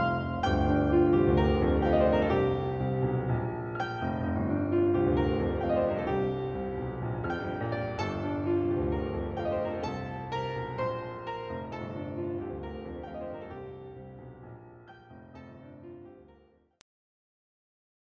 这些专集想最大限度的接近MJ的原始作品，为独奏音乐会的大钢琴编制。